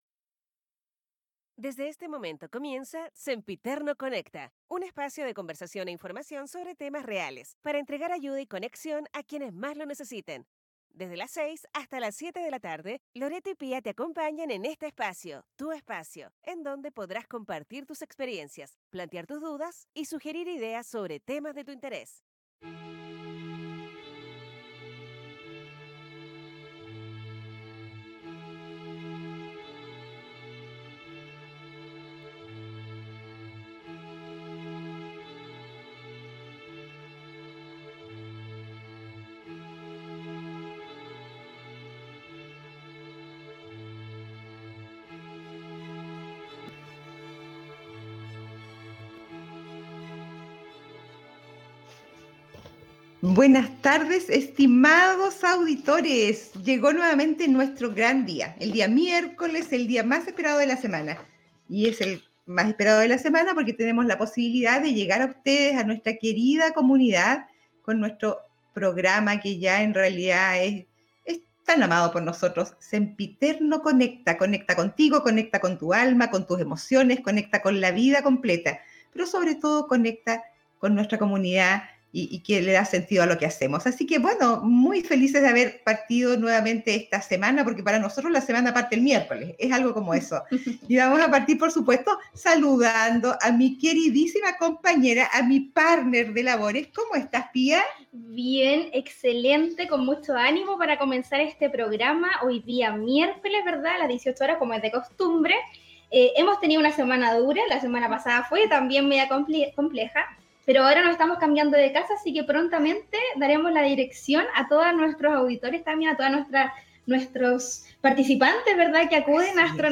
9no capitulo del programa de radio digital: Sempiterno Conecta